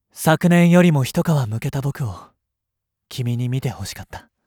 サンプルボイス01 サンプルボイス02 サンプルボイス03 サンプルボイス04 フライパン大学教育学部1年生。
voice_leek03.mp3